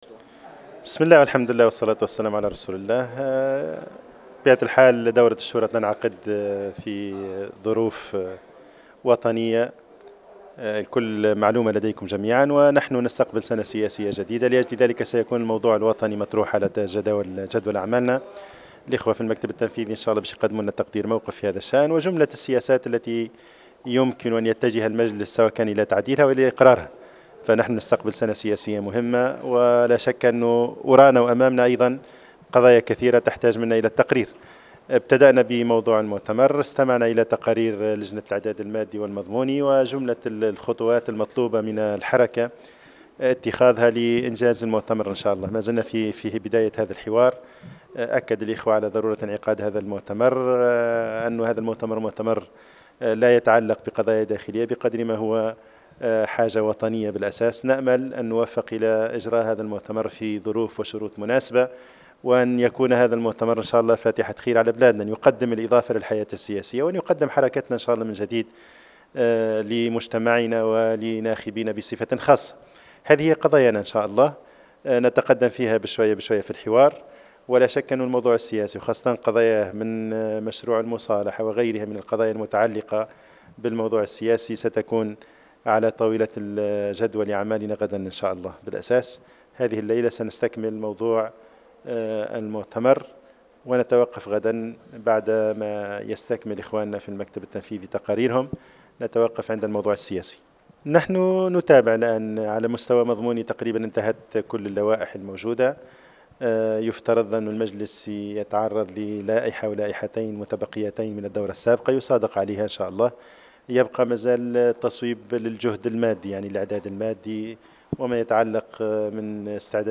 واكد أن حركته لديها موقف أولي من مشروع قانون المصالحة وتحرص على دراسة هذا الموضوع وأنها تلتزم بالعدالة الانتقالية، في تصريحات لـ"الجوهرة أف أم" على هامش انعقاد مجلس الشورى يومي السبت والأحد بالحمامات. ولاحظ أن مشروع القانون في حاجة إلى إجراء تعديلات على هذا القانون حتى ينسجم مع الدستور ومع العدالة الانتقالية.